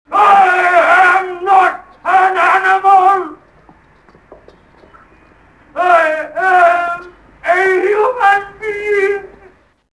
Sound Bites: